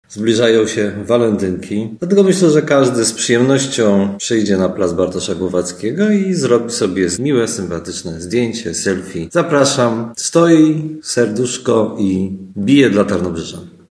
Mówi prezydent Tarnobrzega Dariusz Bożek.